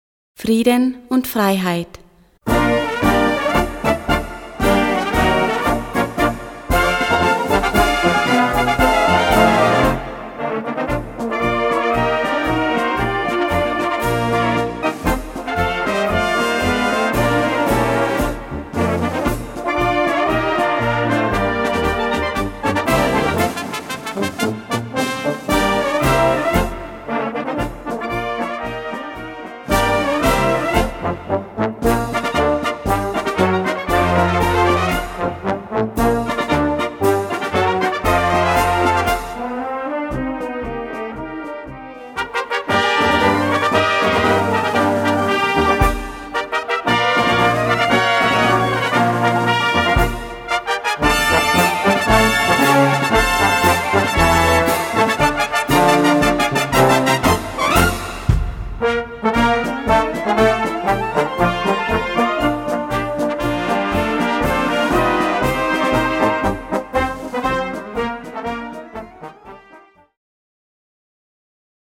Gattung: Konzertmarsch
Besetzung: Blasorchester